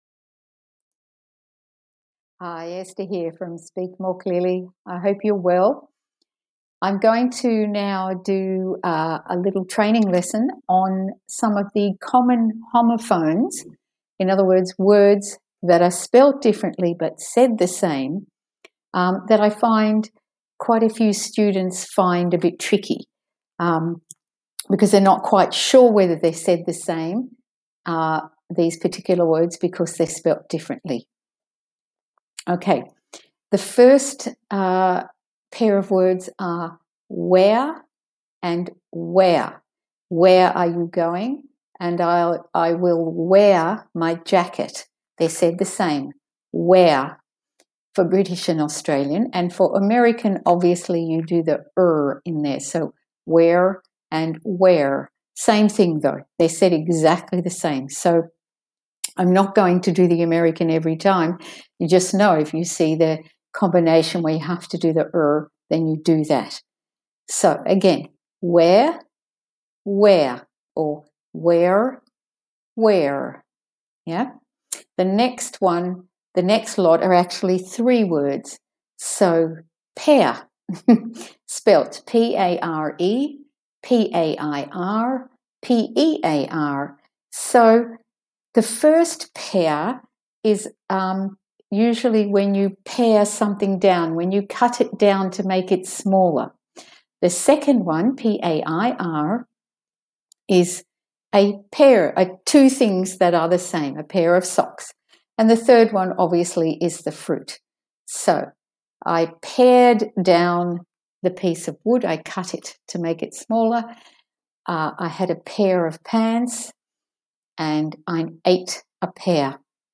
Pronounce tricky English Homophones correctly- Audio training lesson. These are homophones that English students often find tricky, and get confused.
Audio Training lesson
I will include an audio lesson below so you can hear them, and this gives you more English pronunciation practise at the same time!